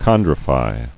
[chon·dri·fy]